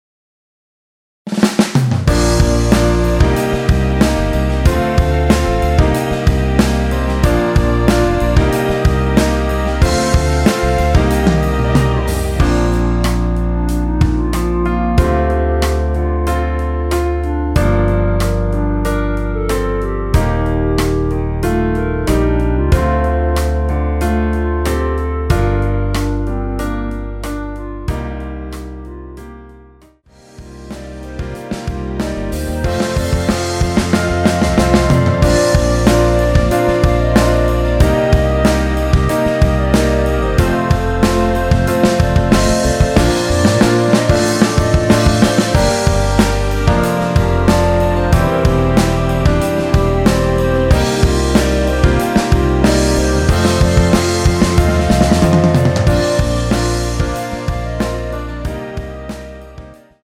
원키에서(-2)내린 멜로디 포함된 MR입니다.(미리듣기 확인)
Bb
앞부분30초, 뒷부분30초씩 편집해서 올려 드리고 있습니다.
중간에 음이 끈어지고 다시 나오는 이유는